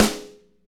SNR MTWN 08L.wav